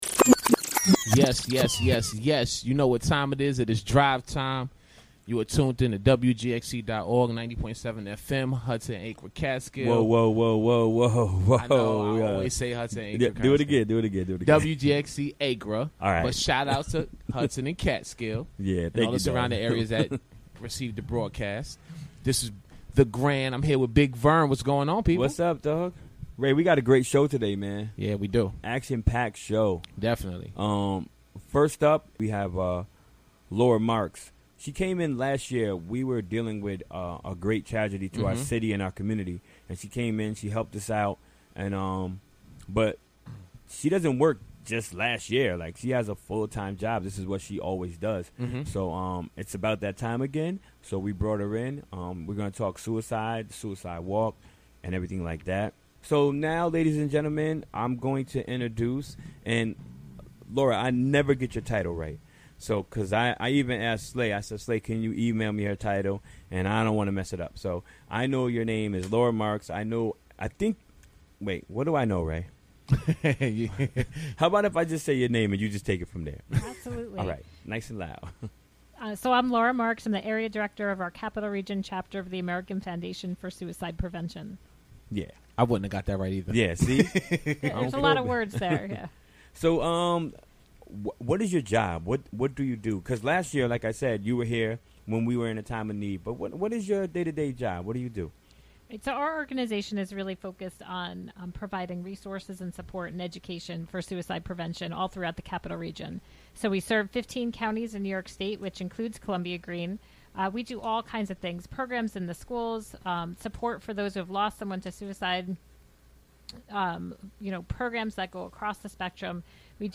WGXC Afternoon Show